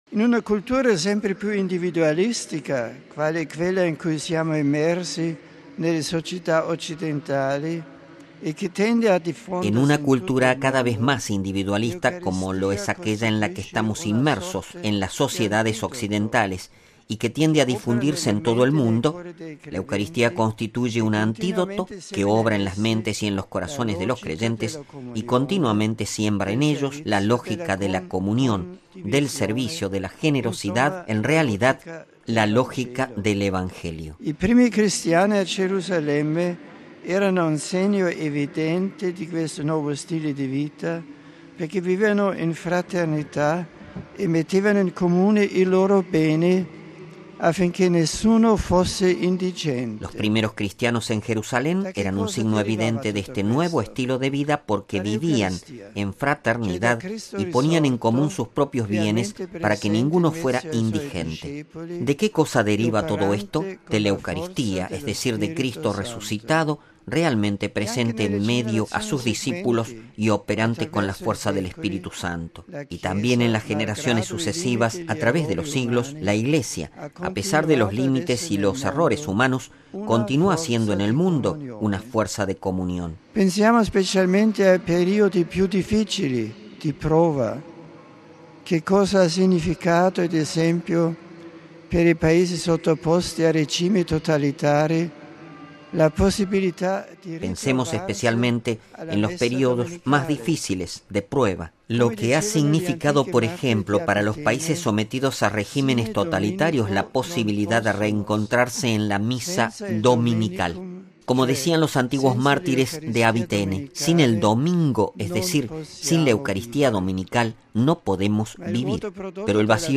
Palabras del Papa en español en el Ángelus del 26 del 6 de 2011 RealAudioMP3